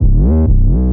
808 ANTIDOTE SLIDES.wav